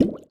etfx_explosion_bubble.wav